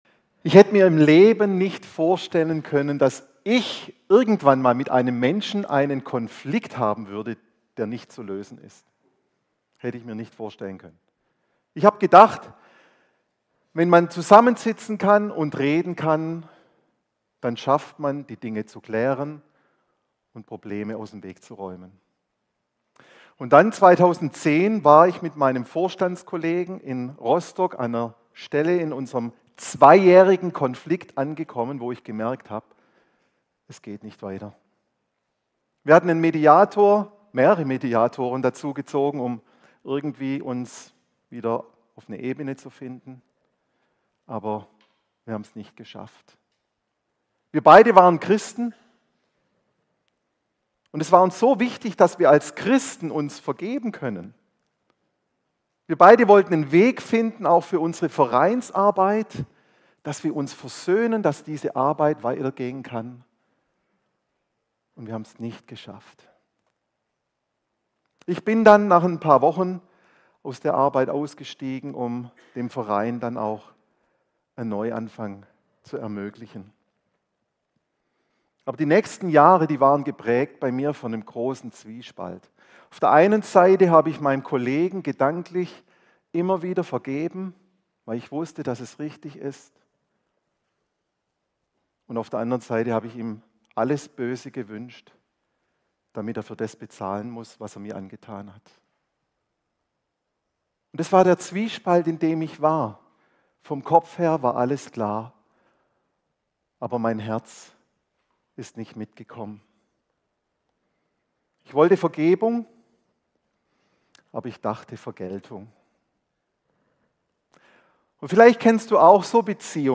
In dieser Predigt geht es um die Kraft der Vergebung – nicht als Theorie, sondern als praktische Erfahrung, die unser Leben und unsere Beziehungen verändert.